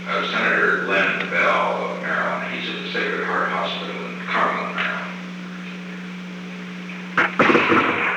The Oval Office taping system captured this recording, which is known as Conversation 498-013 of the White House Tapes.